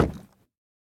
Minecraft Version Minecraft Version 1.21.5 Latest Release | Latest Snapshot 1.21.5 / assets / minecraft / sounds / block / bamboo_wood / step3.ogg Compare With Compare With Latest Release | Latest Snapshot
step3.ogg